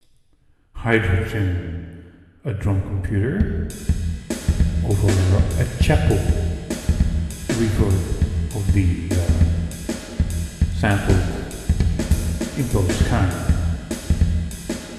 Here are example with a microphone speaking over the impule reverb, and starting a piece of the drum demos, also sounding over the reverb (which is a hard test):